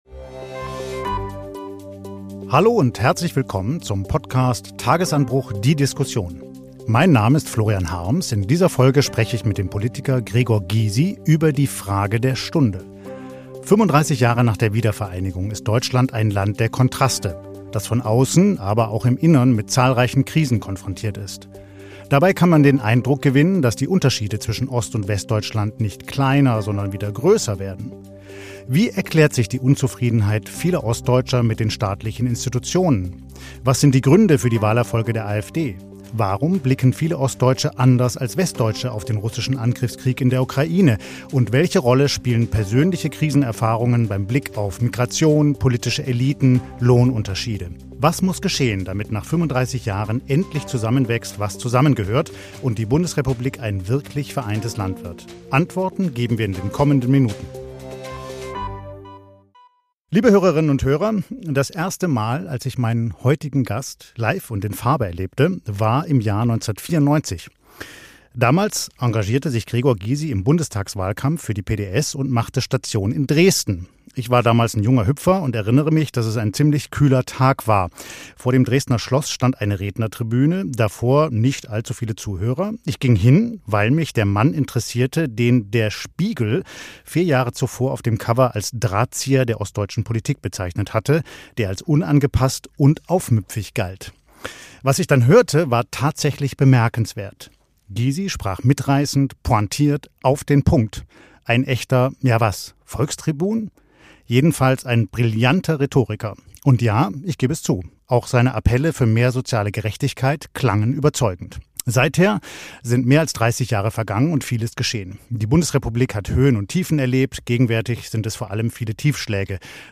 Linken-Politiker Gregor Gysi